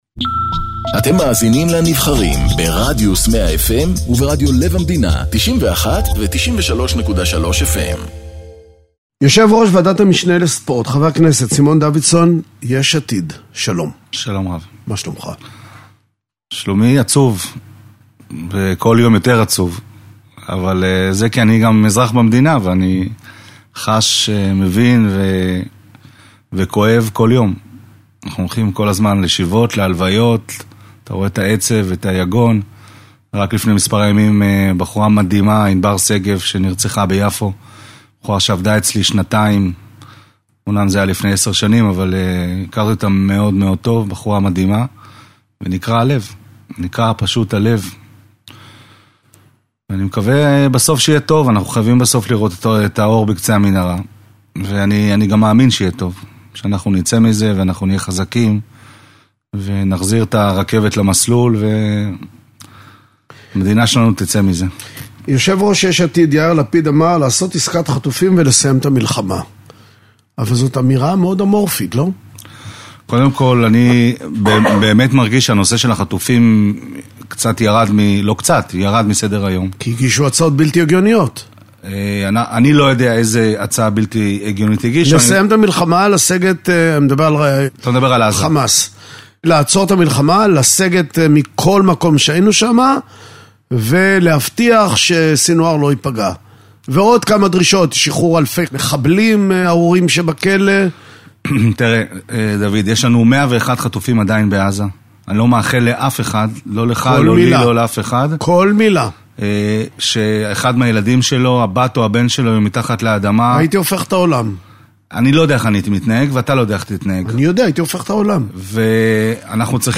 מראיין את חבר הכנסת סימון דוידסון